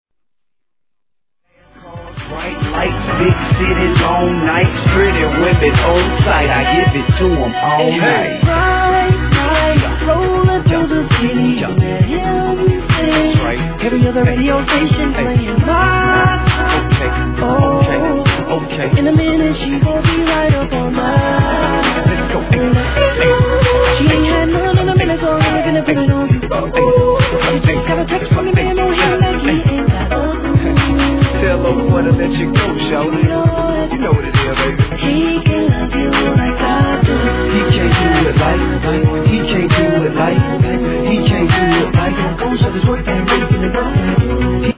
1. HIP HOP/R&B
(BPM 67)